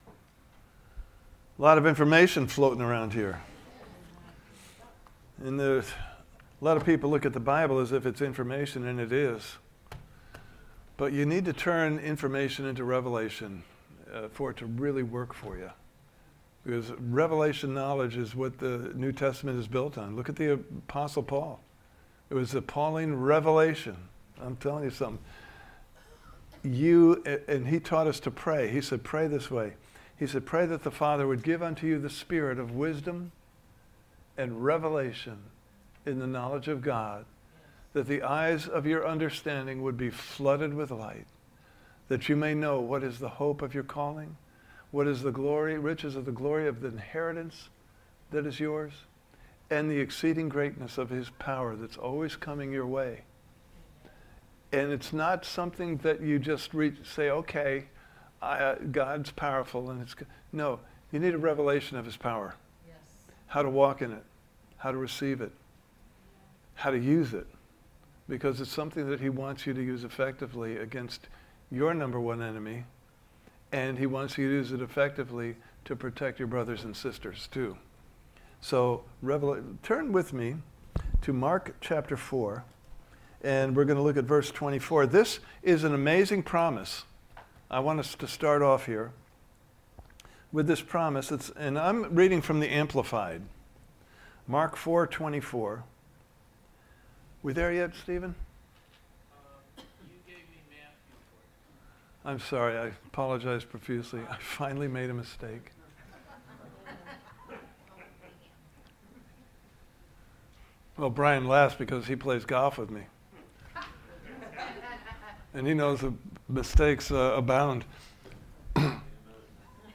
Service Type: Sunday Morning Service « Part 1: The Harvest is Now!